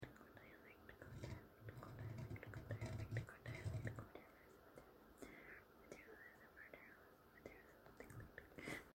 asmr video